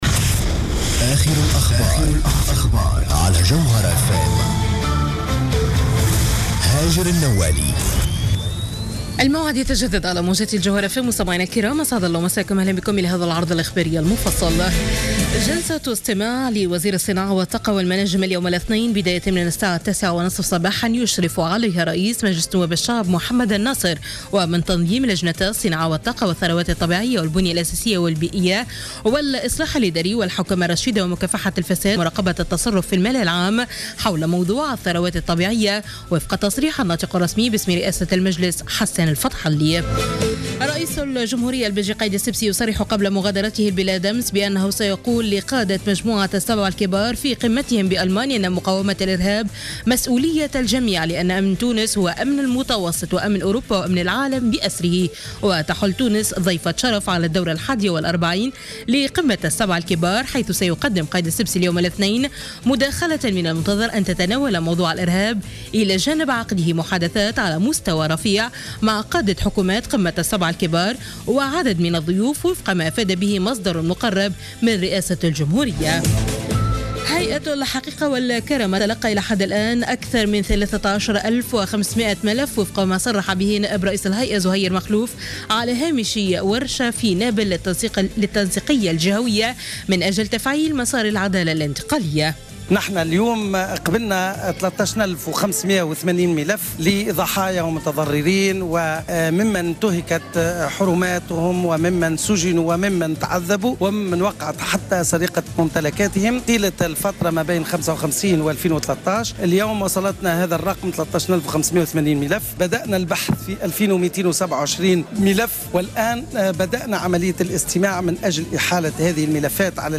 نشرة أخبار منتصف الليل ليوم الإثنين 08 جوان 2015